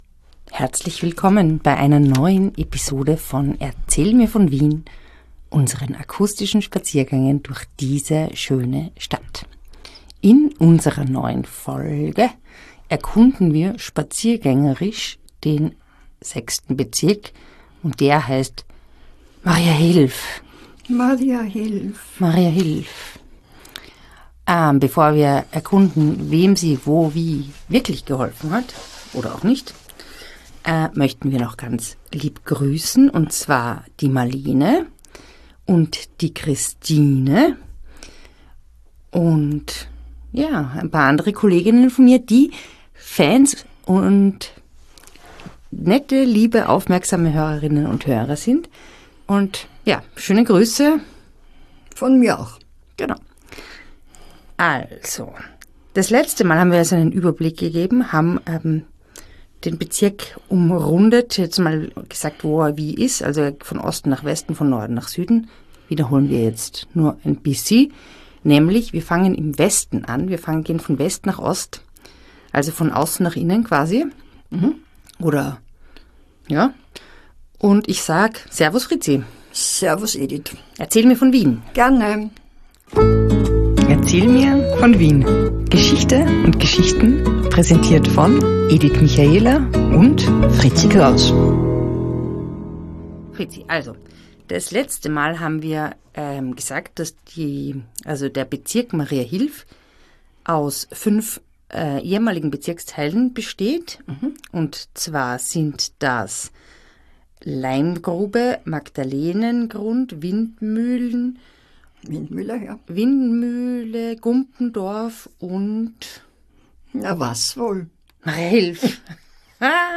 Die beiden spazieren durch Wien und unterhalten sich über bekannte und unbekannte Orte, prägende Persönlichkeiten und die vielen kuriosen Geschichten, die es an allen Ecken, in allen Grätzeln und Bezirken Wien zu entdecken gibt.